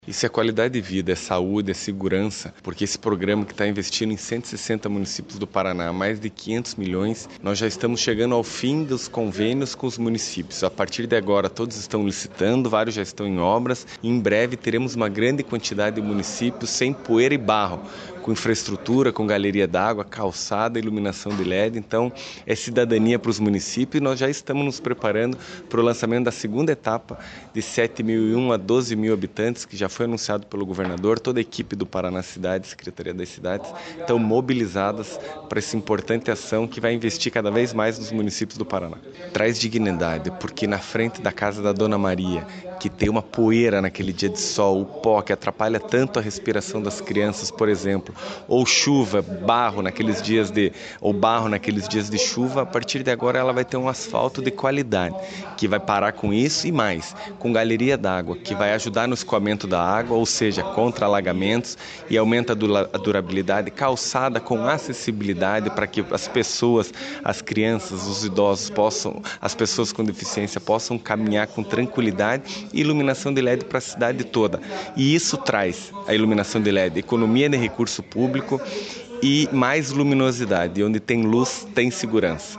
Sonora do secretário das Cidades, Eduardo Pimentel, sobre a liberação de R$ 45,3 milhões para 11 municípios por meio do programa Asfalto Novo, Vida Nova | Governo do Estado do Paraná
Sonora do secretário das Cidades, Eduardo Pimentel, sobre a liberação de R$ 45,3 milhões para 11 municípios por meio do programa Asfalto Novo, Vida Nova